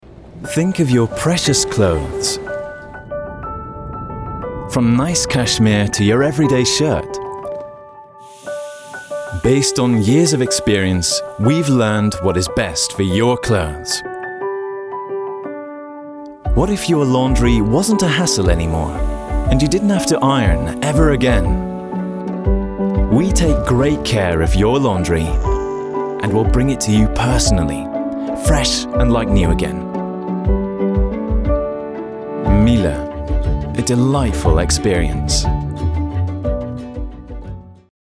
britisch
Sprechprobe: Werbung (Muttersprache):